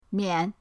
mian5.mp3